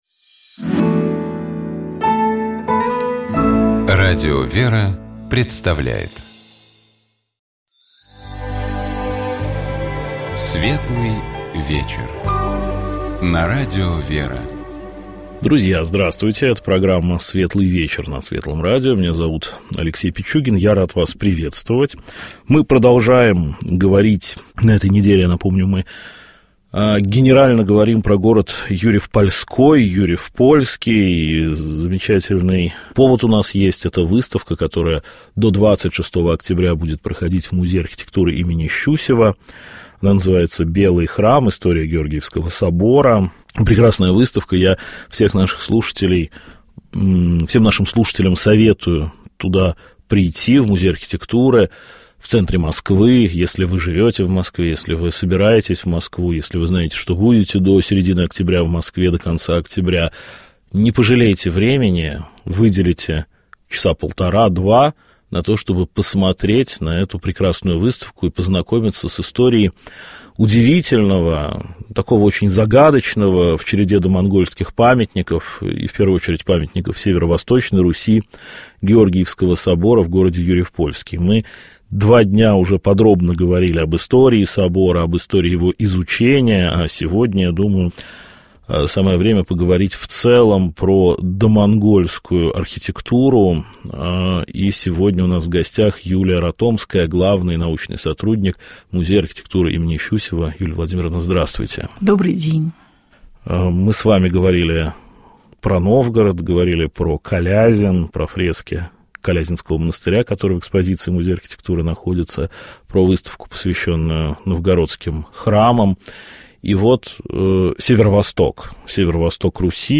«Вечер воскресенья» - это разговор с людьми об их встрече с Богом и приходе к вере. Это разговор о том, как христианин существует в современном мире и обществе, как профессиональная деятельность может гармонично сочетаться с верой.